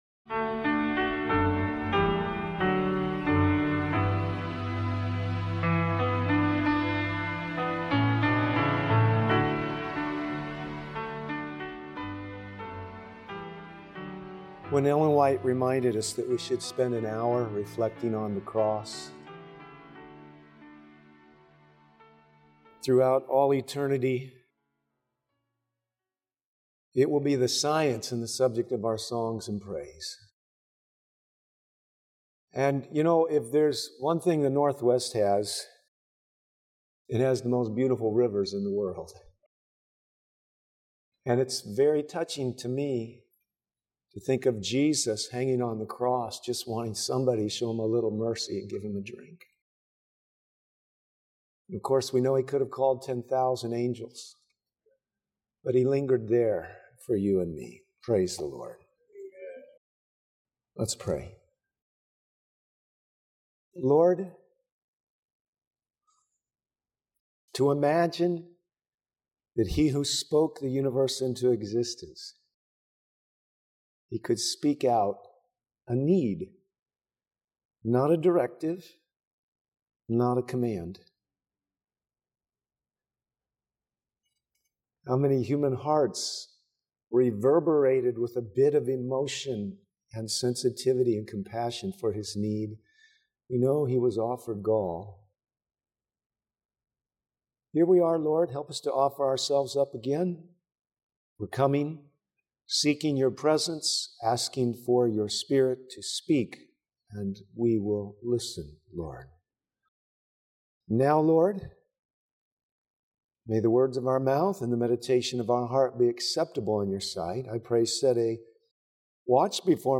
This sermon powerfully explores how the cross of Christ transforms fear into faith, reminding us that God’s perfect love casts out fear and calls us to live with courage. Through Scripture, personal stories, and practical lessons, it shows how trust in God’s presence—not self-reliance or security—enables believers, families, and churches to overcome fear and embrace their mission.